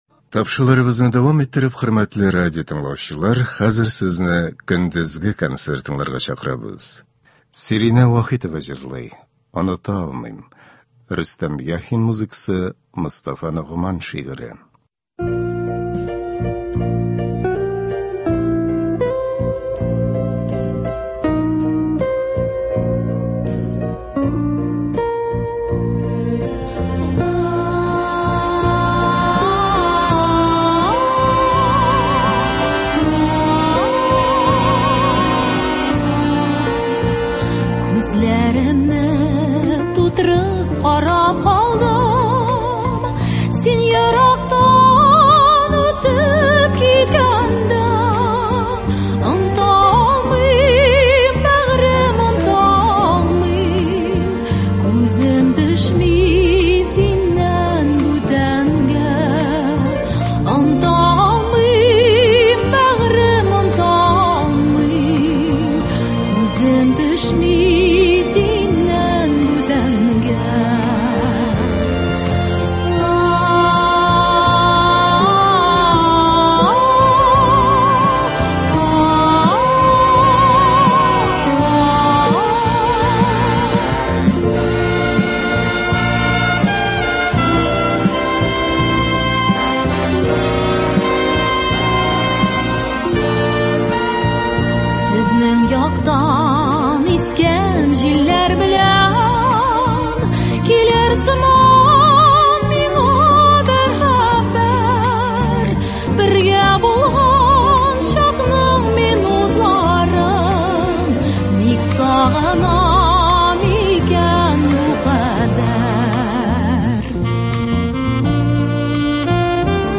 Лирик музыка концерты.